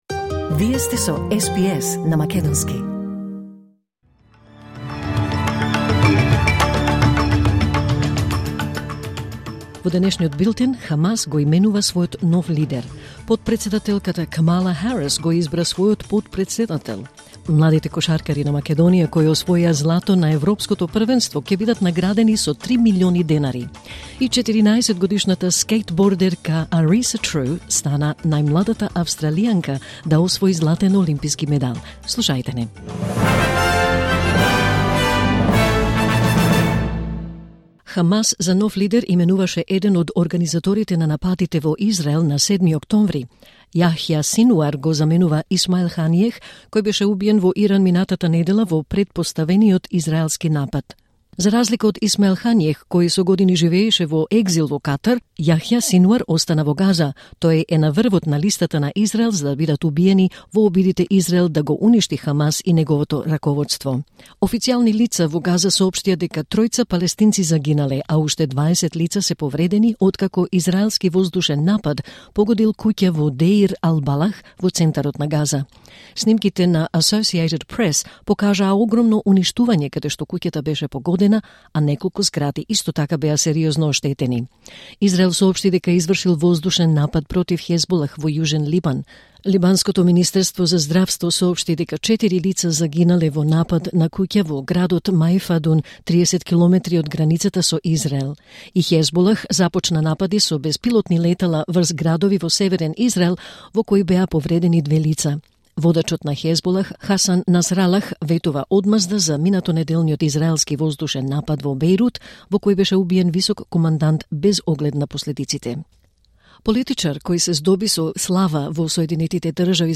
Вести на СБС на македонски 7 август 2024